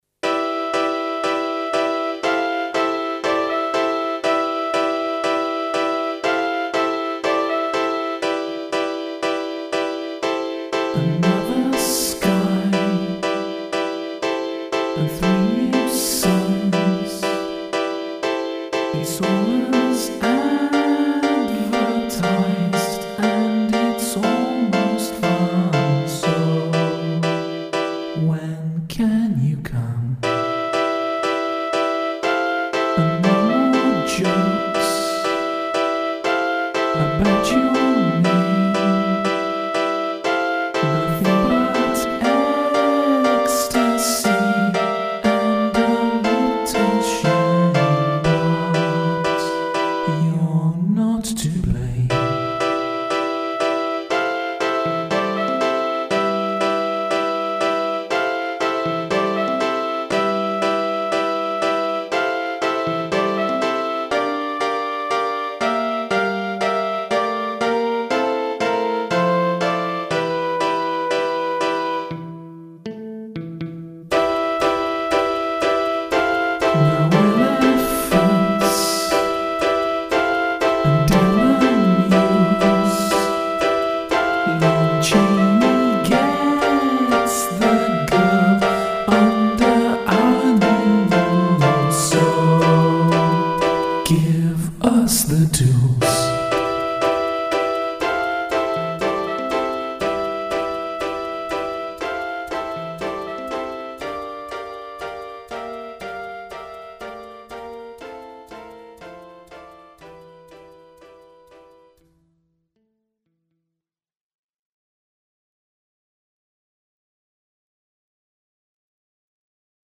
MIDI demo
demo vocals and "enough reverb to make Joe Meek hurl".